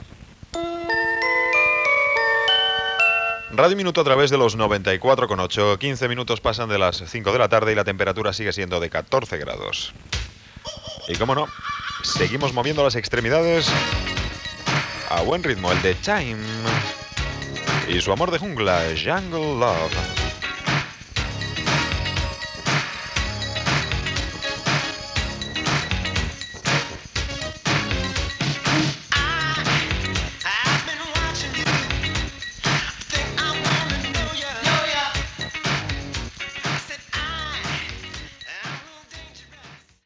dee255de0d1a6ec196bde9773ef1b3965bd709bd.mp3 Títol Radio Minuto Emissora Radio Minuto Titularitat Privada local Descripció Identificació, hora, temperatura i tema musical.